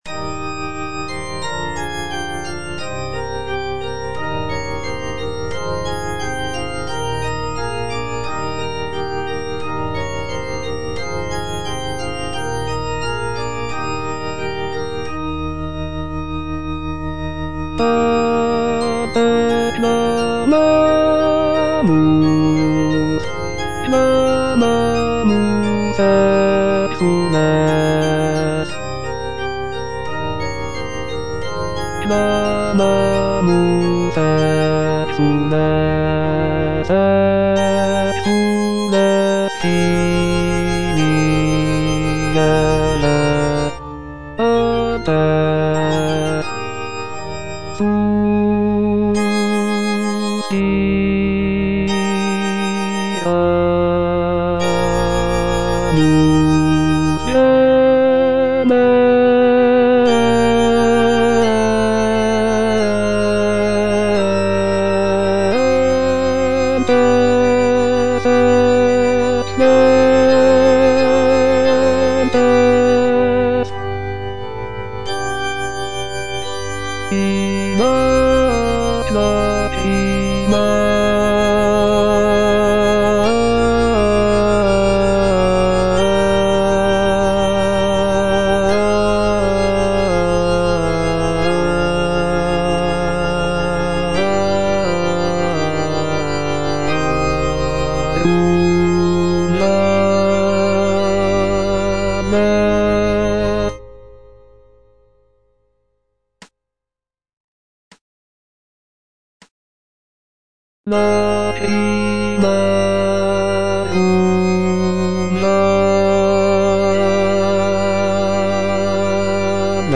G.B. PERGOLESI - SALVE REGINA IN C MINOR Ad te clamamus - Bass (Voice with metronome) Ads stop: auto-stop